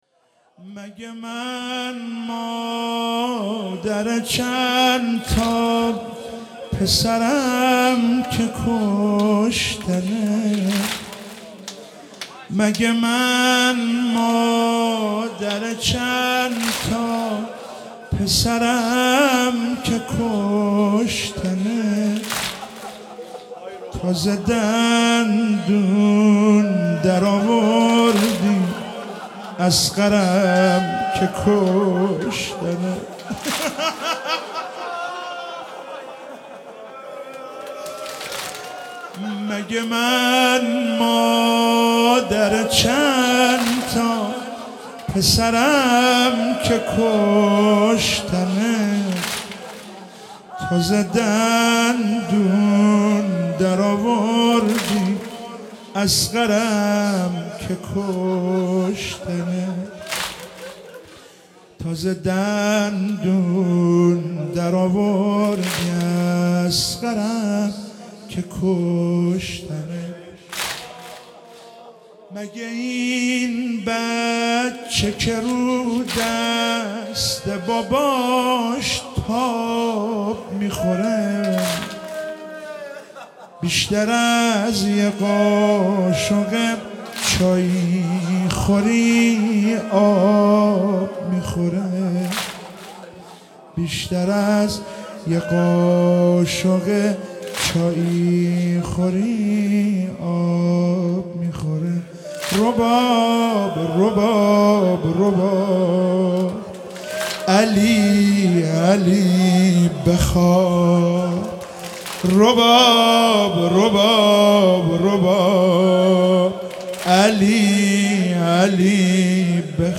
شب هفتم محرم96 - واحد - مگه من مادر چندتا پسرم که کشنت